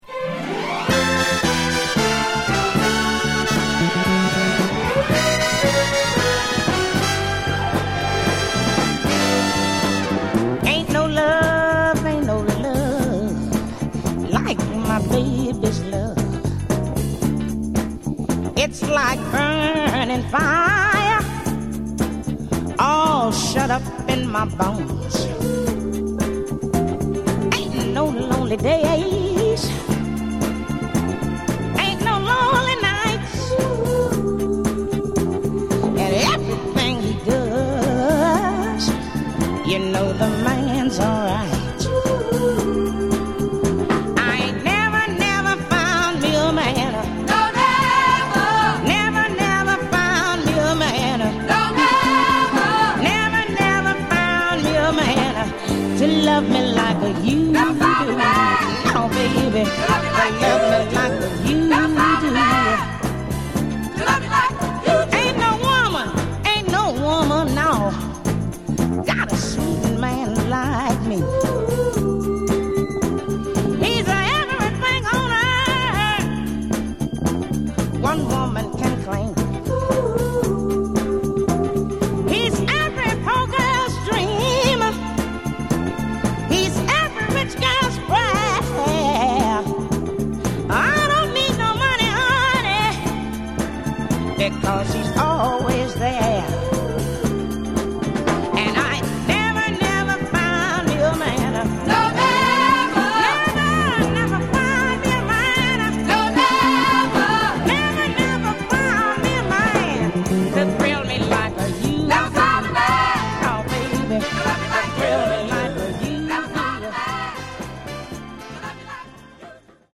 Genre: Other Northern Soul
Top-notch Northern Soul dancer.